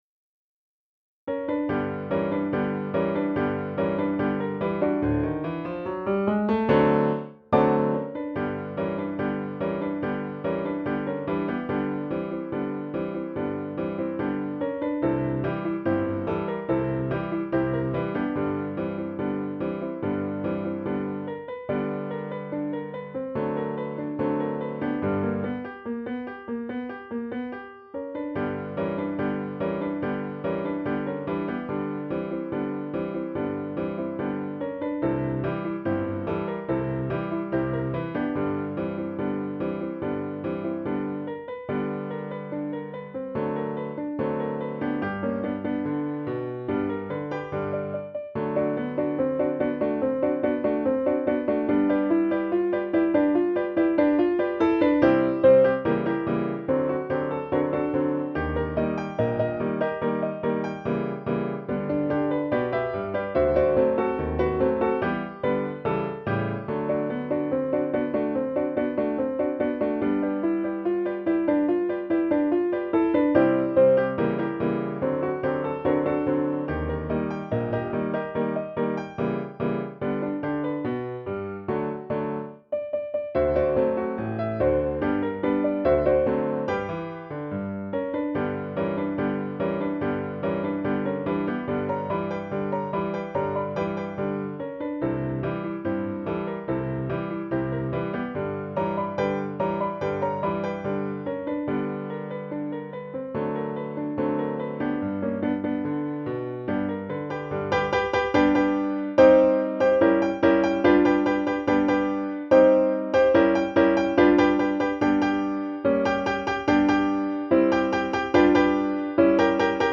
The Jogo Blues (W.C. Handy - 1913) An early instrumental blues number.